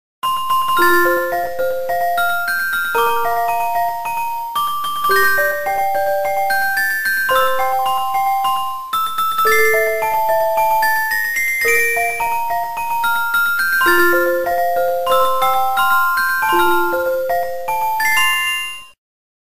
a music box arrangement